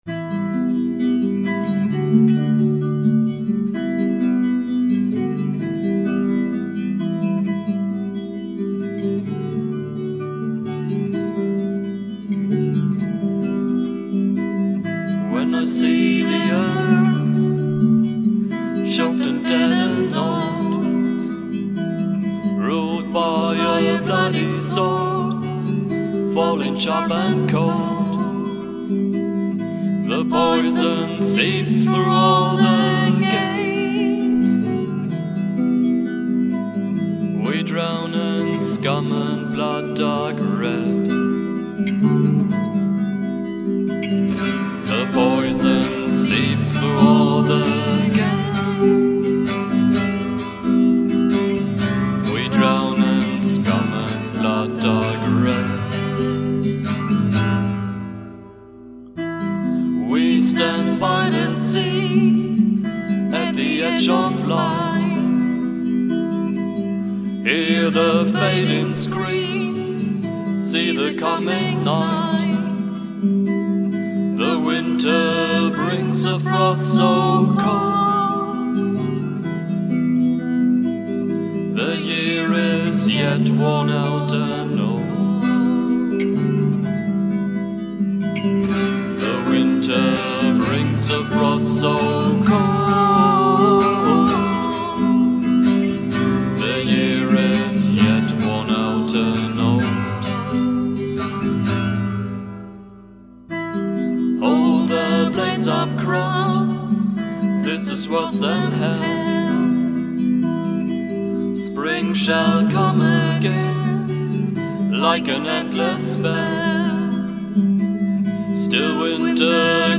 Стиль: Dark Folk